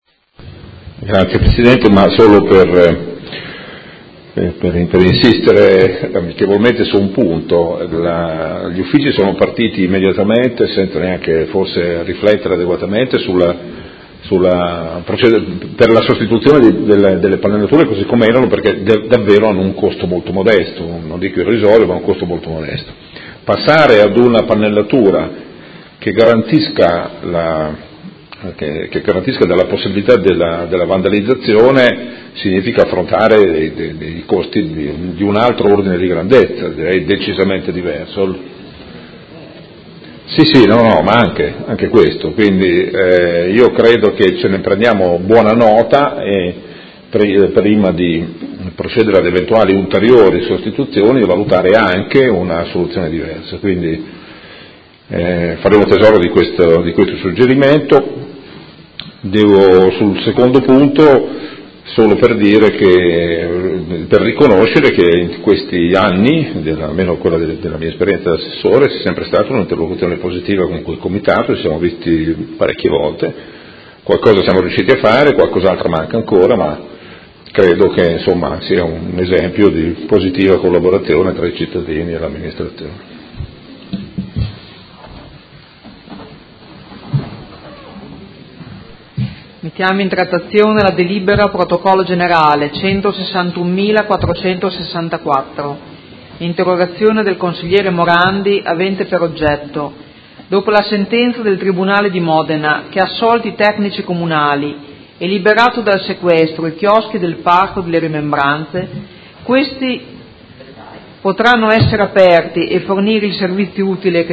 Gabriele Giacobazzi — Sito Audio Consiglio Comunale